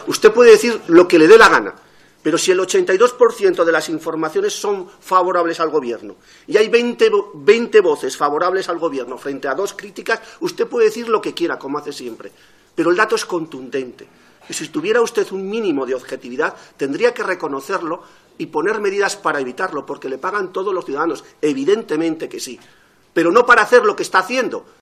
Felix Lavilla en la Comisión de control de RTVE 28/04/2015